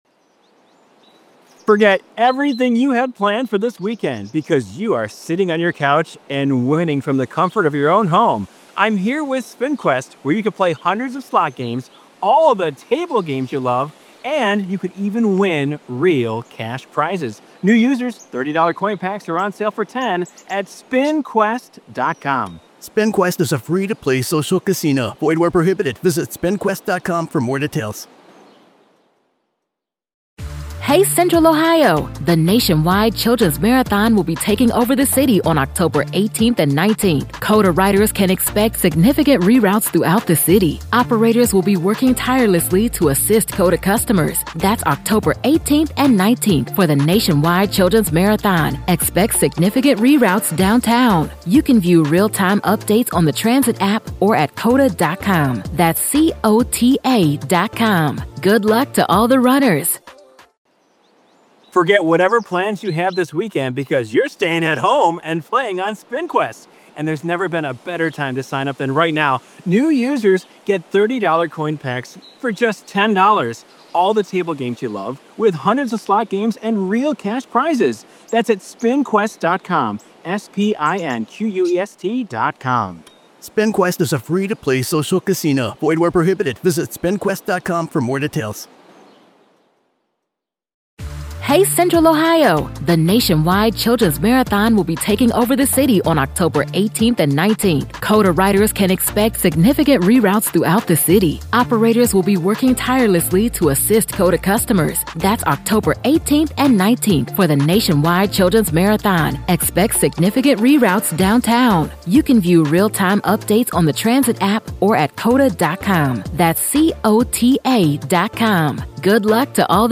True Crime Today | Daily True Crime News & Interviews / Can the Jury Find Alex Murdaugh Not Guilty?